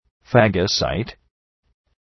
Προφορά
{‘fægə,saıt}